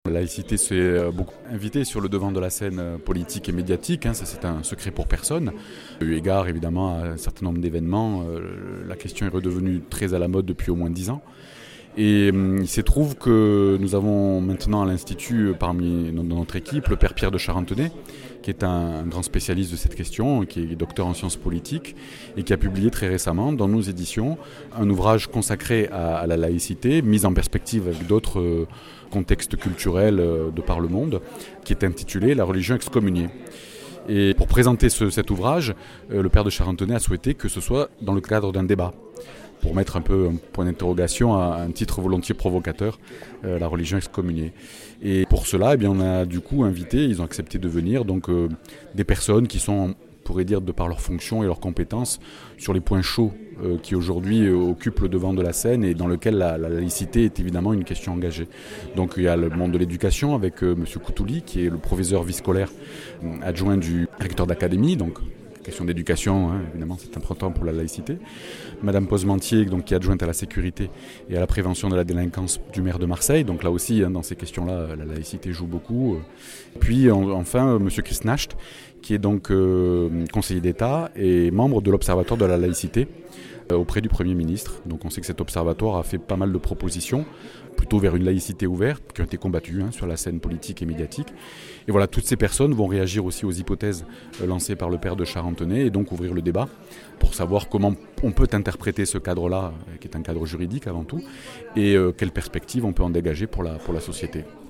Marseille: riche débat à l’Institut Catholique de la Méditerranée sur ce que devient la laïcité